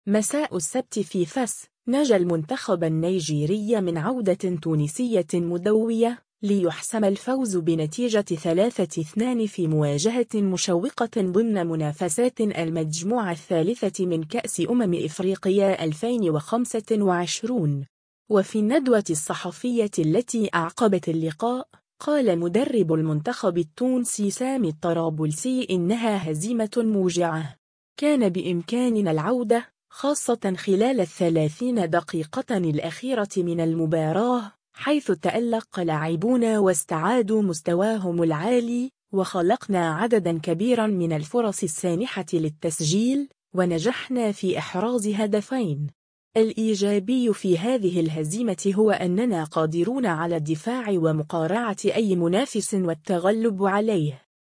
وفي الندوة الصحفية التي أعقبت اللقاء، قال مدرب المنتخب التونسي سامي الطرابلسي إنّها «هزيمة موجعة. كان بإمكاننا العودة، خاصة خلال الثلاثين دقيقة الأخيرة من المباراة، حيث تألق لاعبونا واستعادوا مستواهم العالي، وخلقنا عدداً كبيراً من الفرص السانحة للتسجيل، ونجحنا في إحراز هدفين.